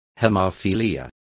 Also find out how hemofilias is pronounced correctly.